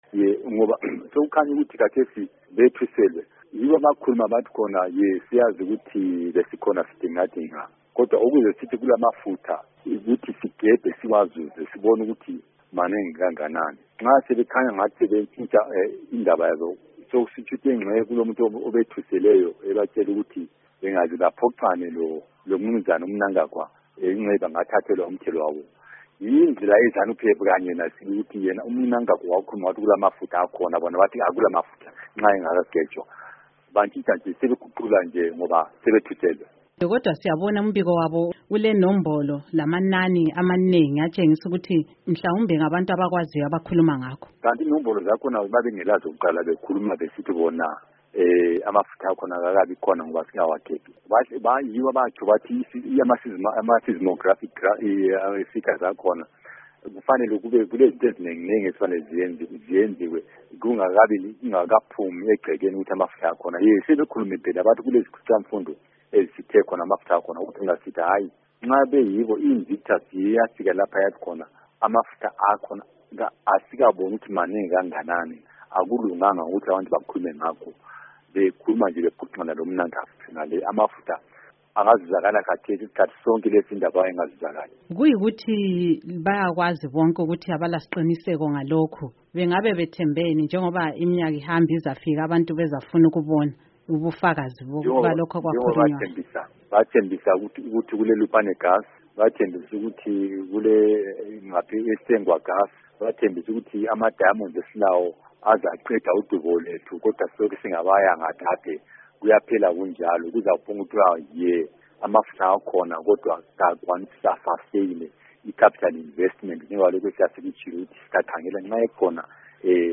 Hurukuro na Va Prosper Mutseyami